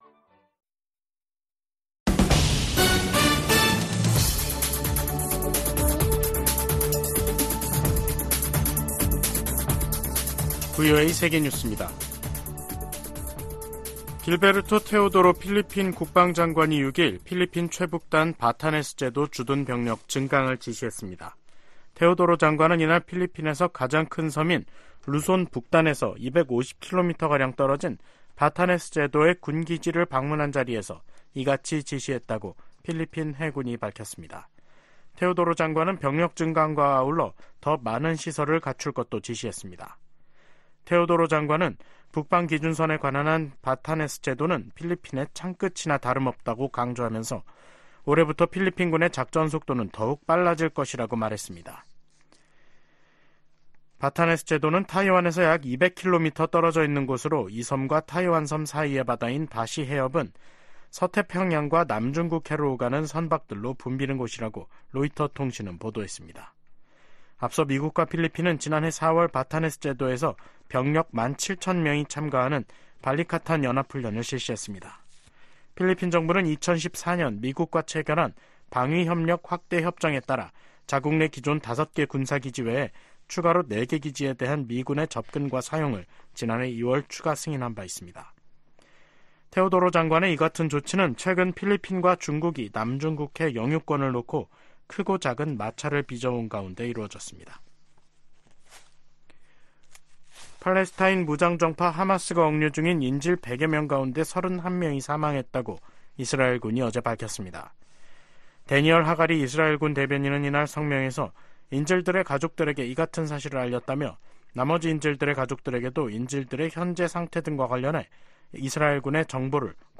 VOA 한국어 간판 뉴스 프로그램 '뉴스 투데이', 2024년 2월 7일 3부 방송입니다. 미국이 유엔 안보리에서 러시아의 북한제 무기 사용을 강하게 비난하고, 양국에 책임 묻기를 촉구했습니다. 한국 신임 외교부 장관과 중국 외교부장이 첫 통화를 하고 현안을 논의했습니다.